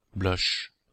Ääntäminen
Ääntäminen Belgique, Brabant wallon: IPA: /blœʃ/ Haettu sana löytyi näillä lähdekielillä: ranska Käännös 1. руж {m} (ruž) Suku: m .